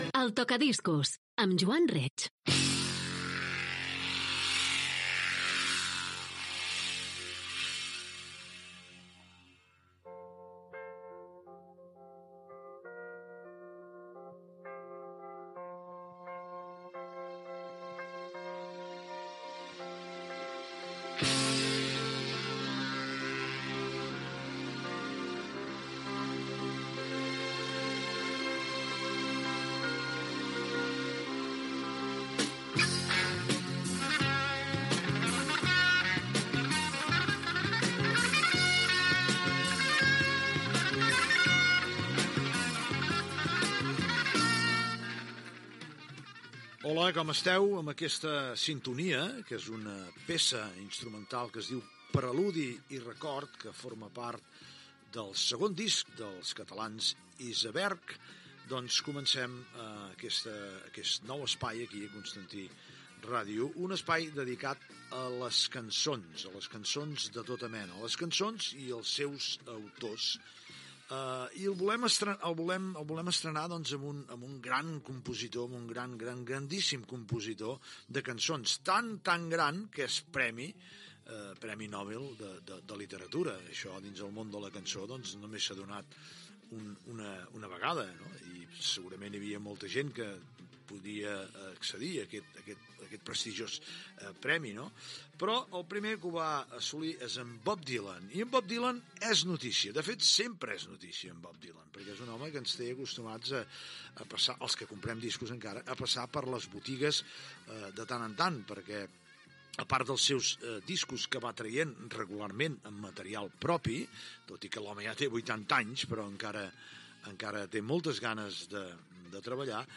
Careta del programa, sintonia i presentació del programa
Gènere radiofònic Musical